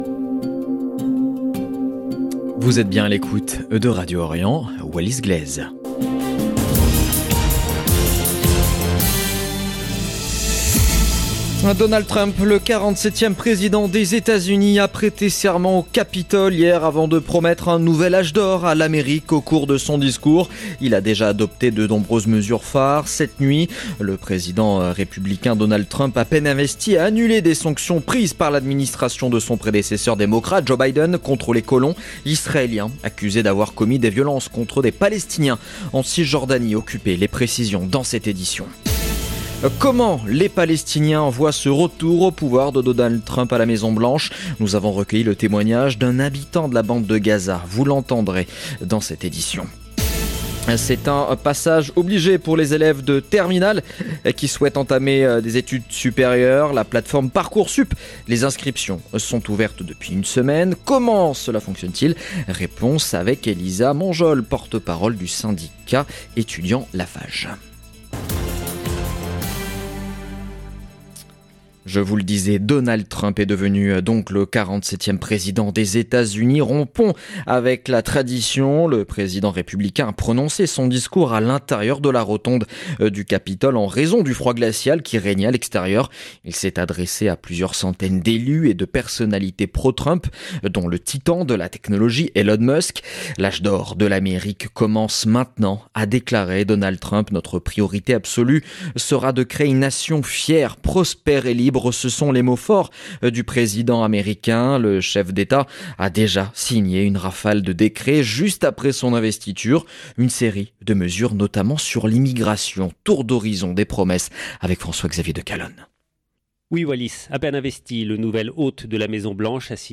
LE JOURNAL EN LANGUE FRANÇAISE DE MIDI DU 21/01/2025
Comment les Palestiniens voient ce retour au pouvoir de Donald Trump ? Nous avons recueillis le témoignage d’un habitant de la bande de Gaza.